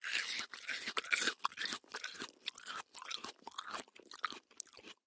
Звуки арбуза: Как звучит поедание арбуза